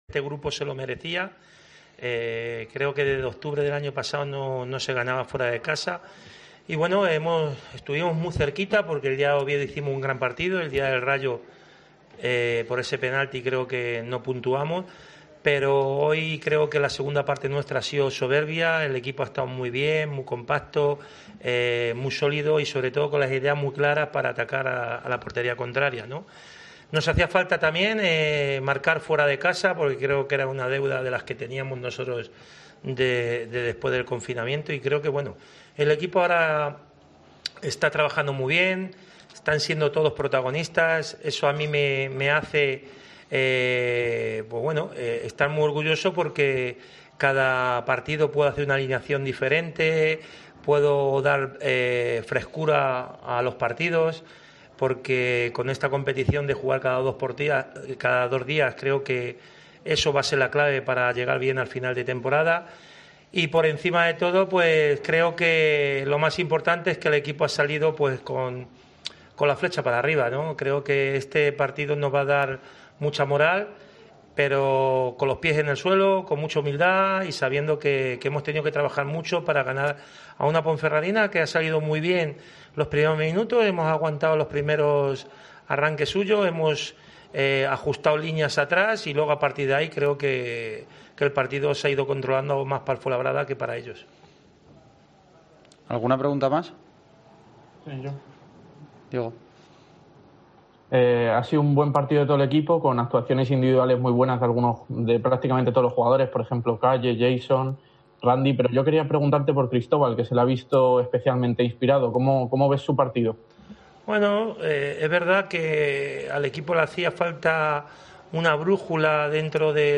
AUDIO: Escucha aquí al entrenador del Fuenalabrada que consiguió ganar en El Toralín 0-3 a la Deportiva Ponferradina